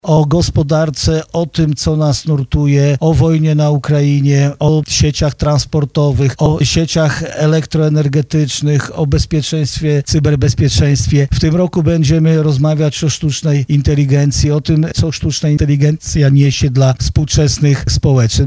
-tłumaczy Marszałek Jarosław Stawiarski